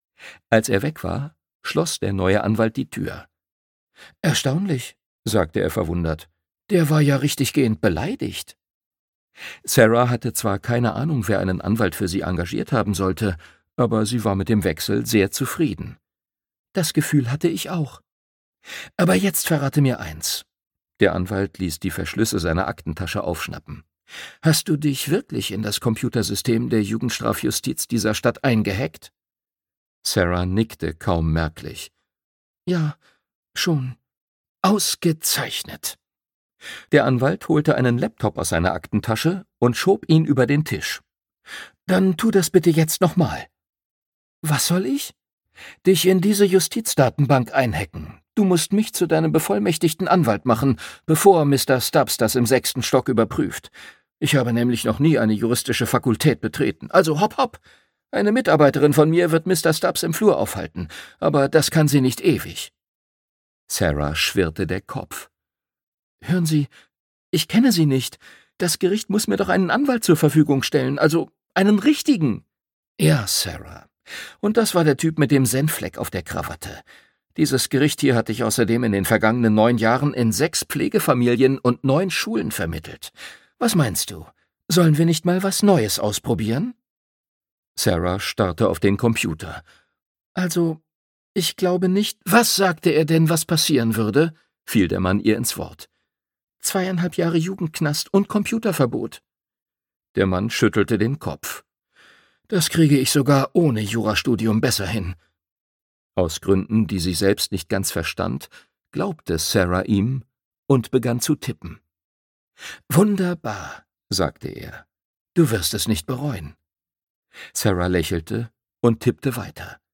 City Spies 1: Gefährlicher Auftrag - James Ponti - Hörbuch